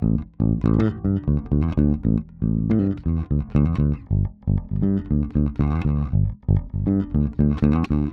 28 Bass PT1.wav